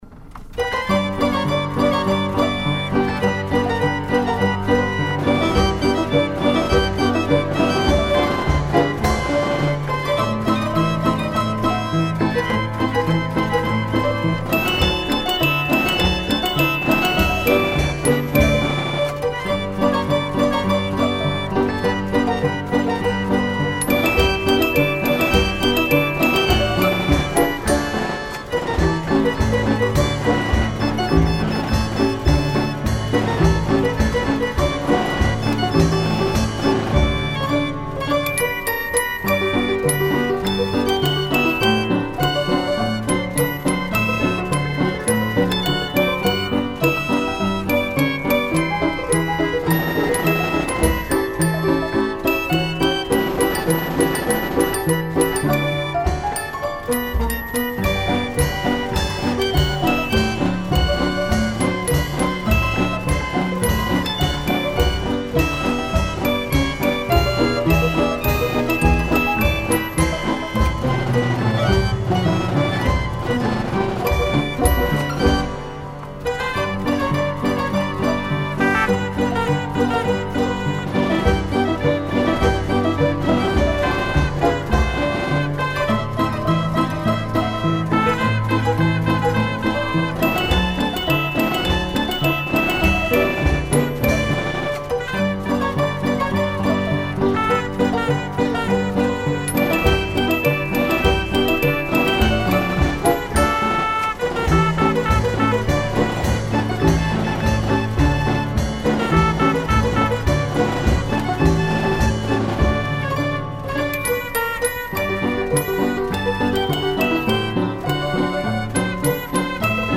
Polka sur un orchestrion
danse : polka
Emissions de la radio RCF Vendée
musique mécanique